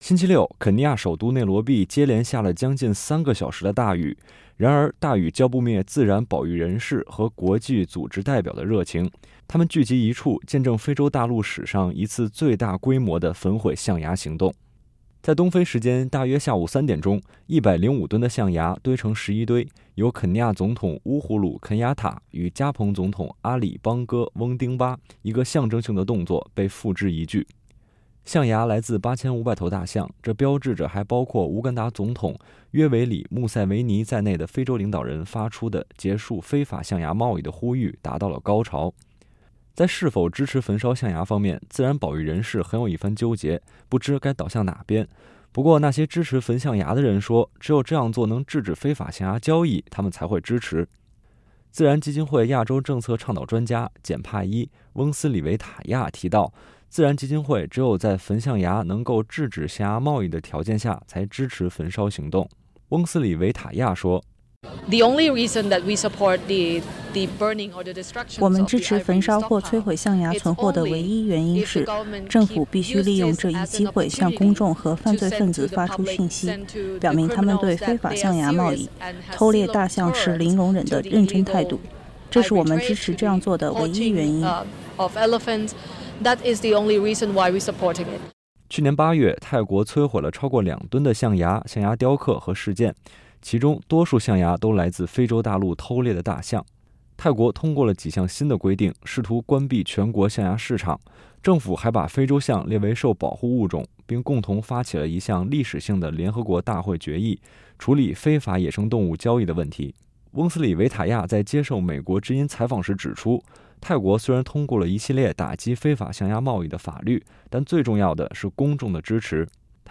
美国之音记者采访了自然基金会(WWF)的一位亚洲代表。